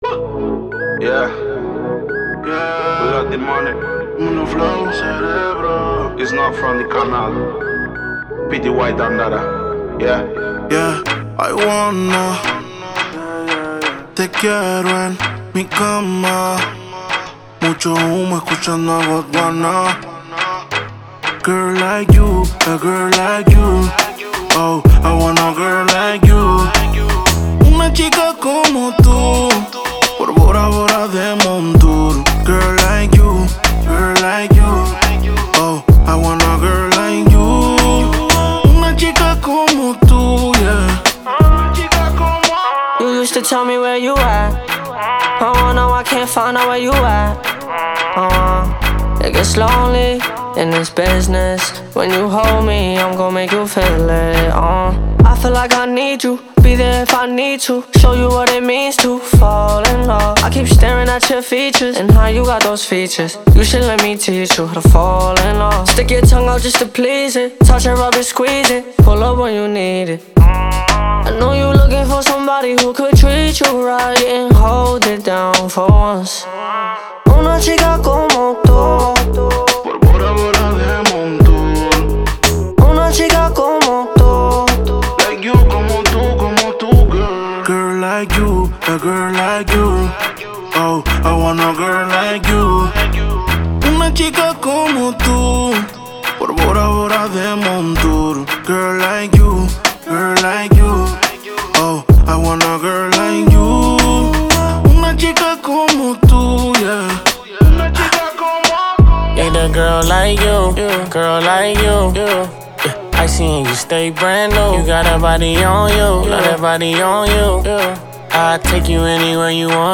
это зажигательная композиция в жанре реггетон и хип-хоп